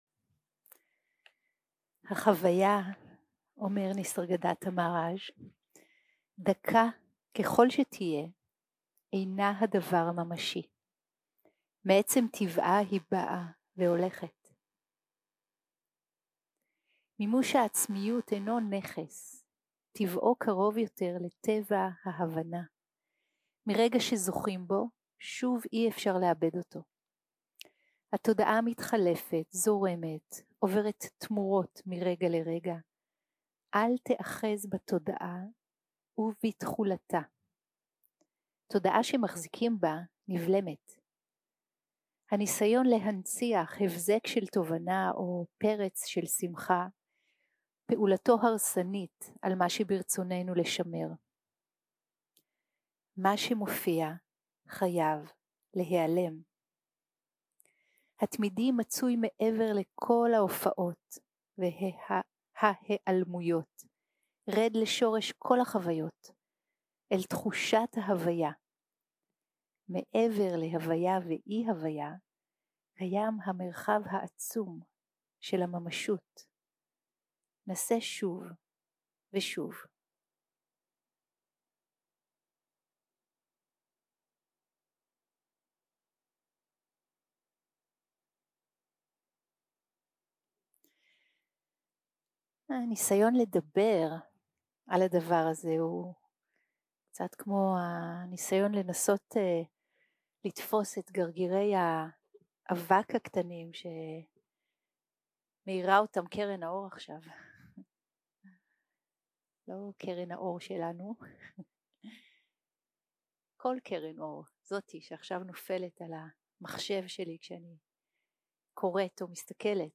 יום 4 - הקלטה 8 - בוקר - הנחיות למדיטציה
סוג ההקלטה: שיחת הנחיות למדיטציה